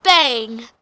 BANG2.WAV